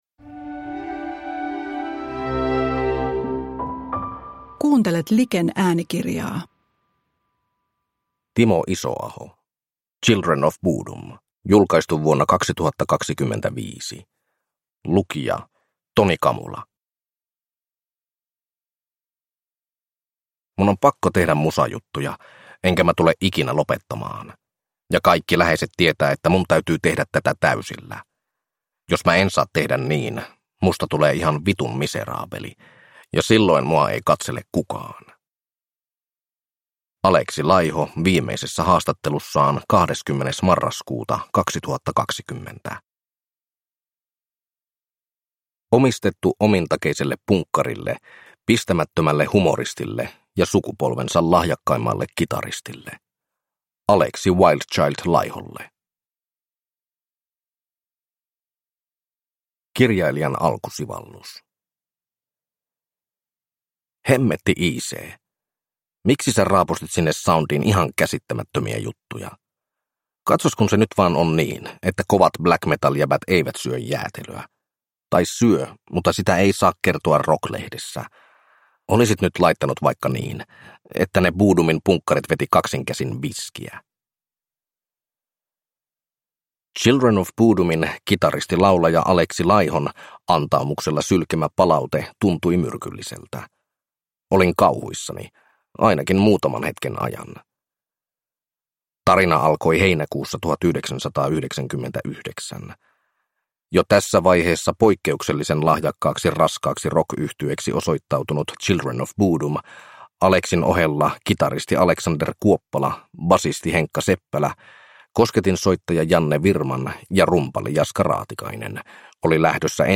Children of Bodom – Ljudbok